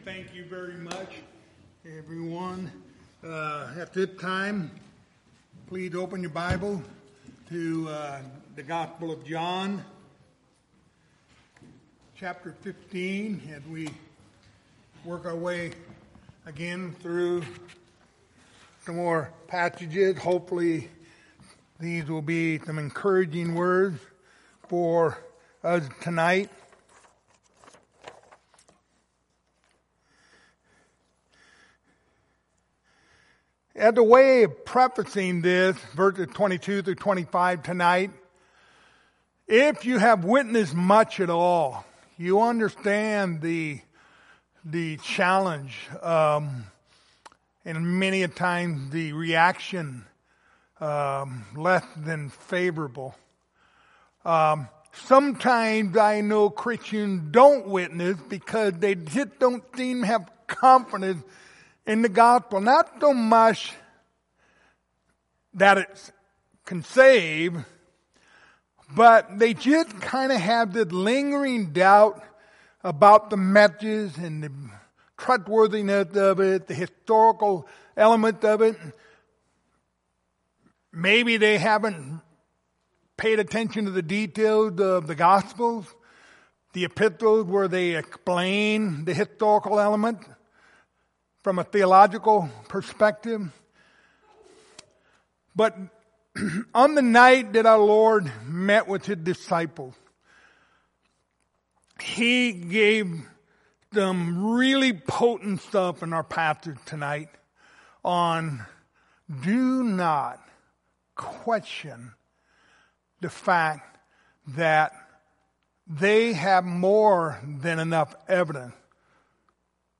Passage: John 15:22-25 Service Type: Wednesday Evening